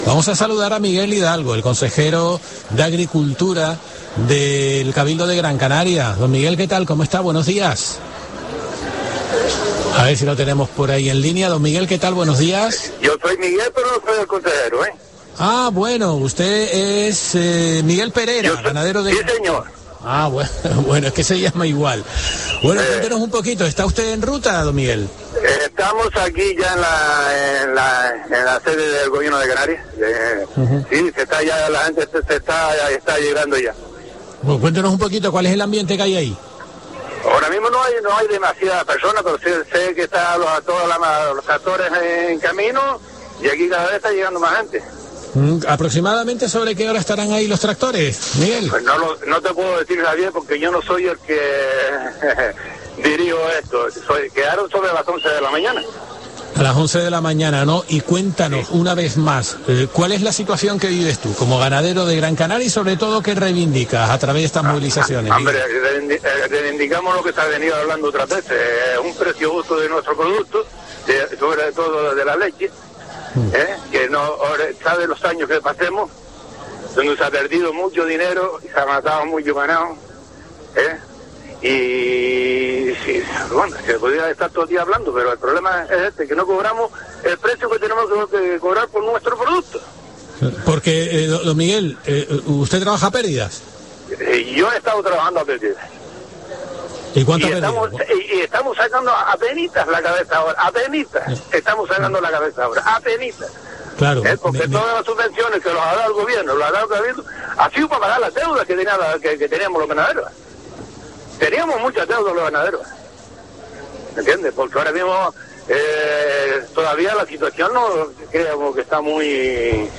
ganadero de Gran Canaria